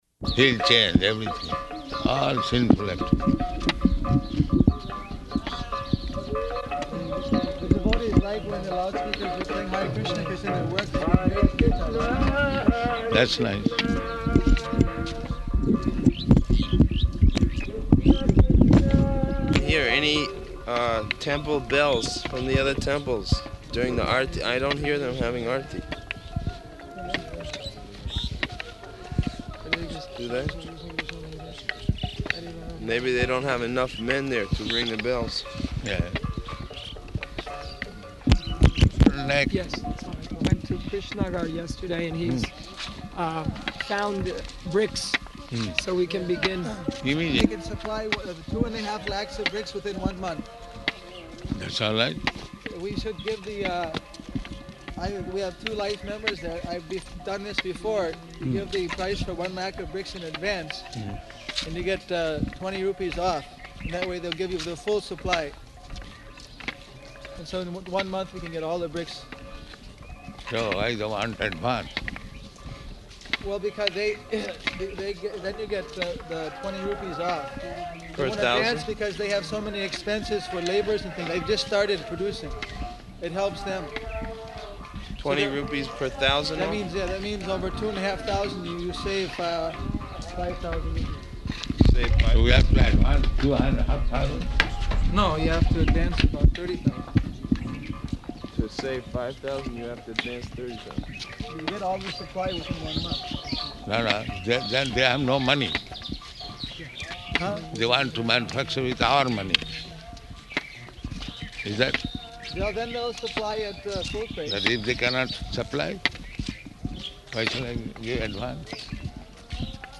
Tour of Māyāpur Grounds --:-- --:-- Type: Walk Dated: January 17th 1976 Location: Māyāpur Audio file: 760117MW.MAY.mp3 Prabhupāda: ...He'll change everything, all sinful activities.